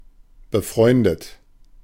Ääntäminen
Synonyymit mates Ääntäminen US : IPA : /fɹɛn(d)z/ Haettu sana löytyi näillä lähdekielillä: englanti Käännös Ääninäyte Substantiivit 1.